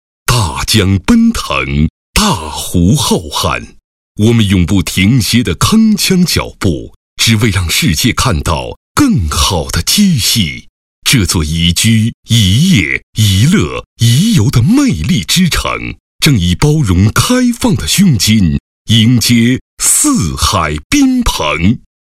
语言：普通话 （143号男）
擅长：专题片 广告
特点：大气浑厚 稳重磁性 激情力度 成熟厚重
风格:浑厚配音